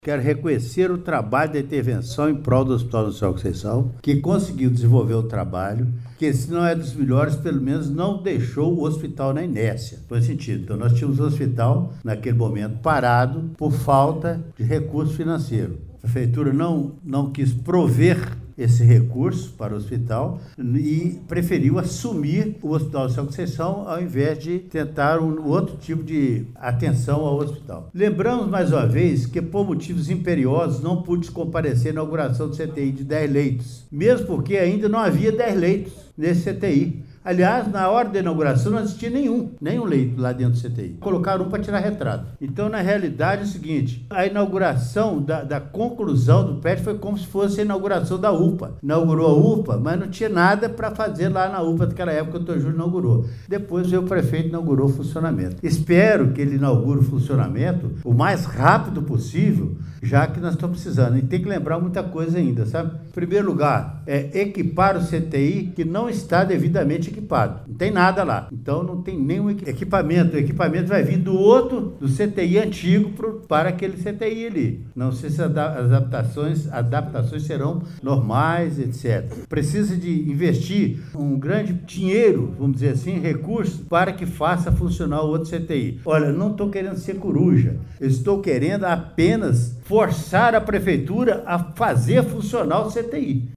Em seguida foi realizada a fase dos oradores inscritos para usarem a tribuna livre do Poder Legislativo.